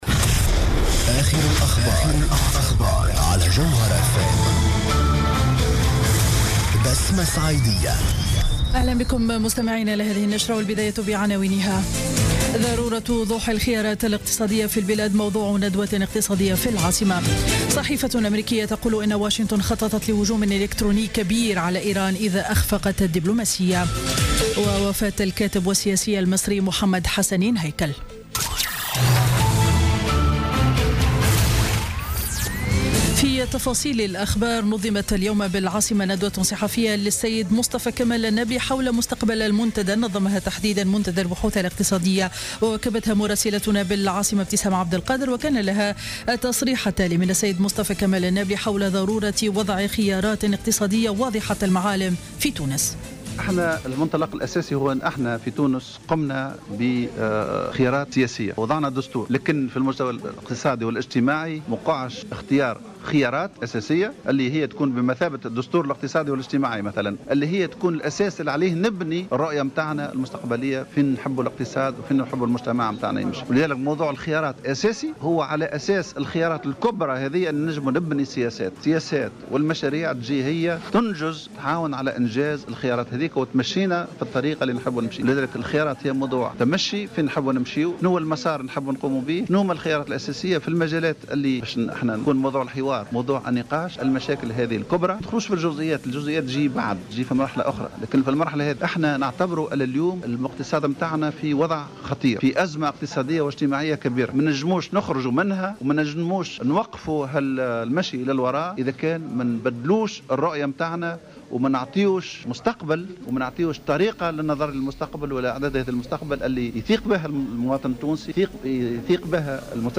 نشرة أخبار منتصف النهار ليوم الأربعاء 17 فيفري 2016